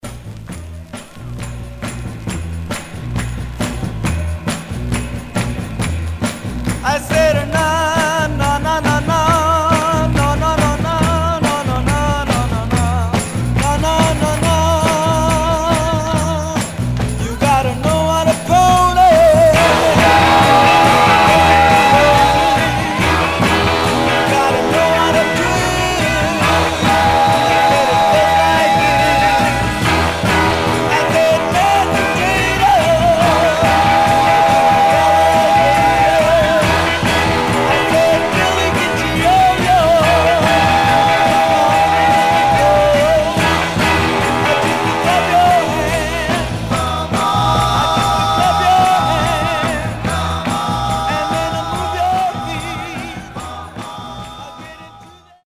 Stereo/mono Mono
Rock